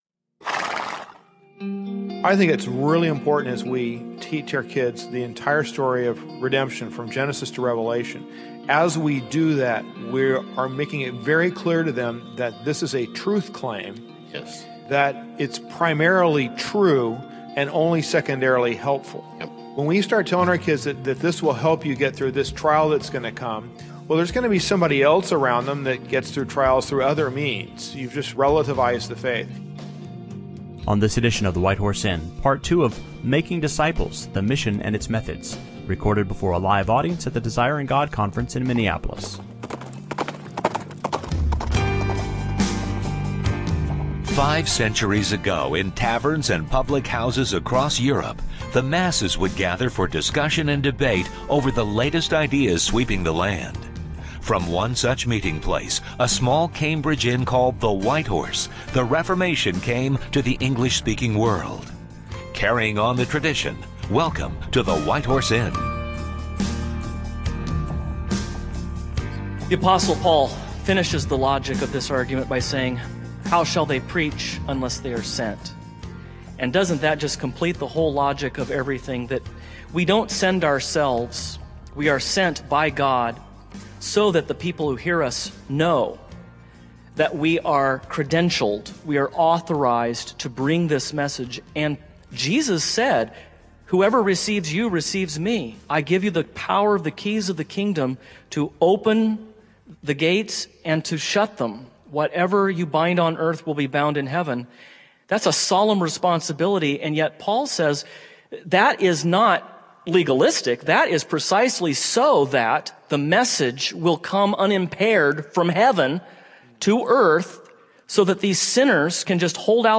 On this special edition of the White Horse Inn recorded before a live audience at the Desiring God conference in Minneapolis, the hosts continue their discussion of the marks and mission of the Church based on the words of the Great Commission, and also take questions from the audience.